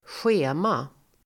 Uttal: [sj'e:ma (el. ²sj'e:-)]